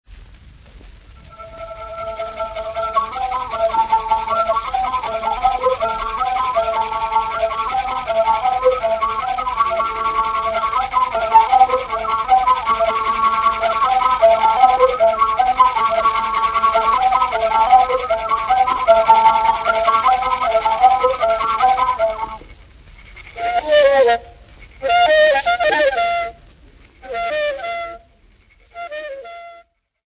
Popular music--Africa
Field recordings
sound recording-musical
Self delectative song accompanied by the Chizambi friction bow